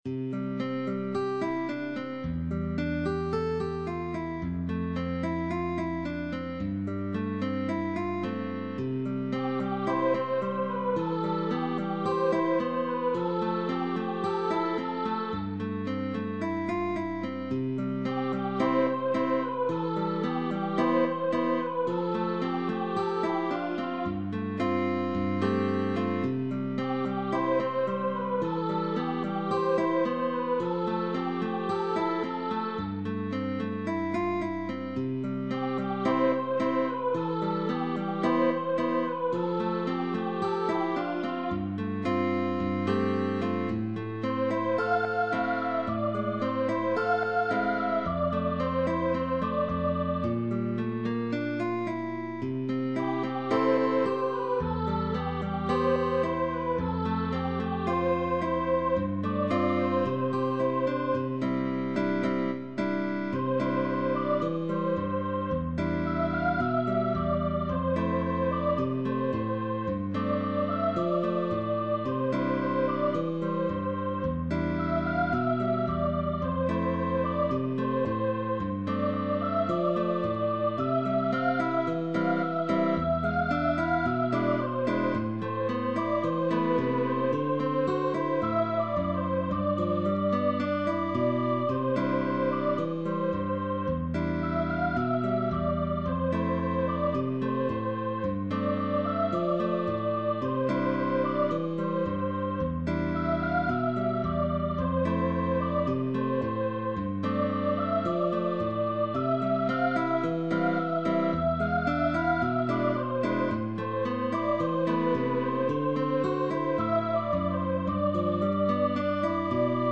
Arrangement for voice with guitar accompaniment.